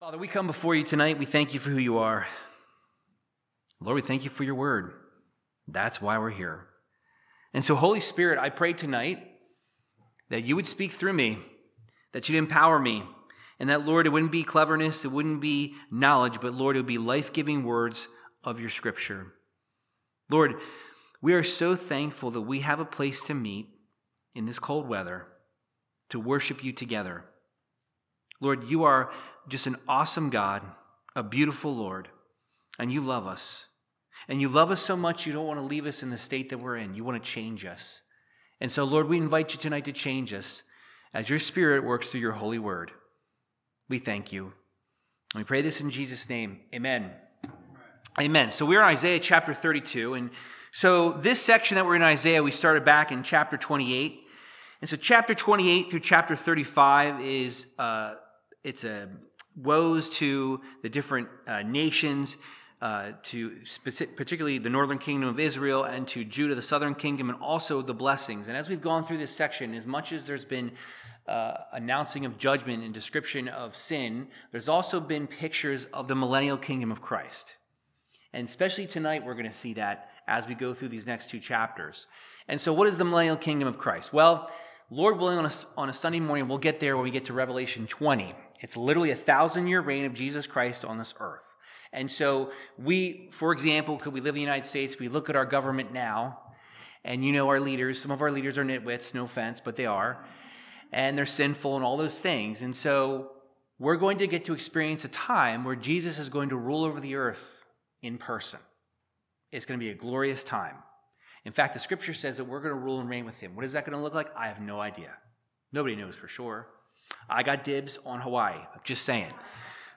Verse by verse Bible teaching through the book if Isaiah chapters 32 and 33